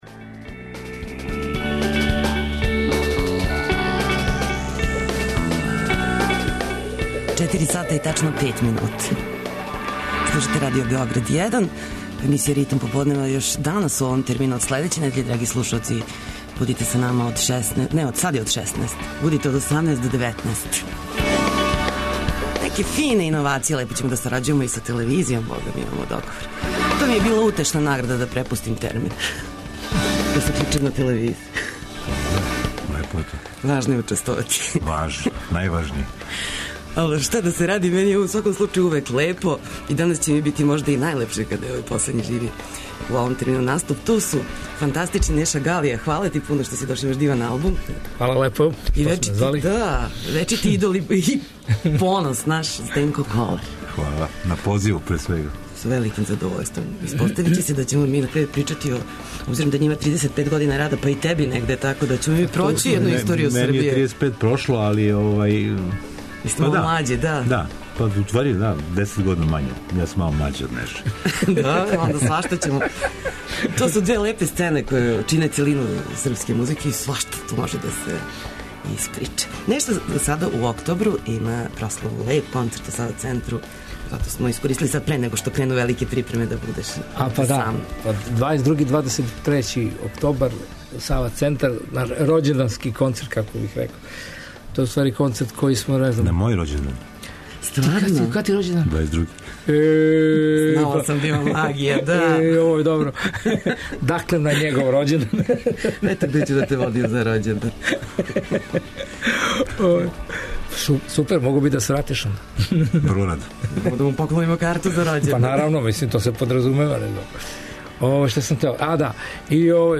Гост емисије је Неша Галија, који ће говорити о концертима "Галије" која ове године прославља 35 година постојања. Чланови групе припремили су за концерте посебан репертоар, сачињен скоро искључиво од балада, за ову прилику урађених у новим аранжманима.